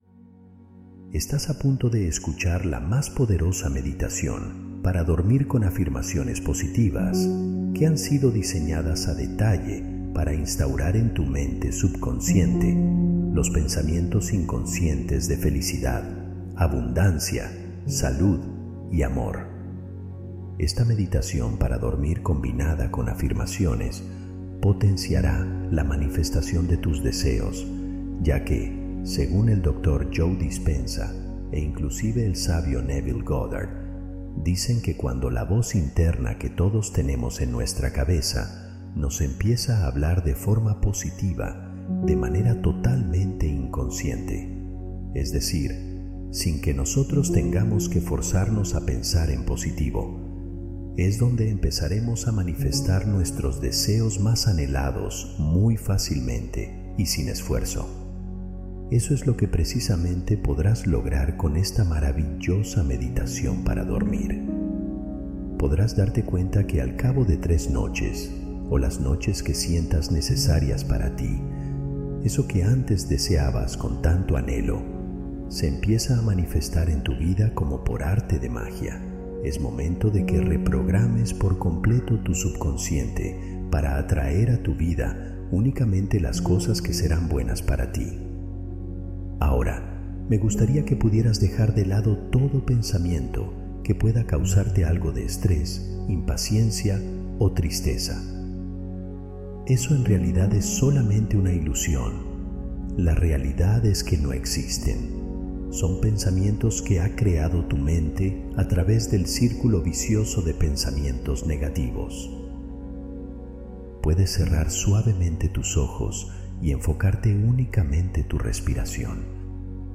Manifiesta Milagros Mientras Duermes | Meditación Nocturna